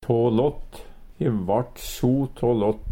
tå lått - Numedalsmål (en-US)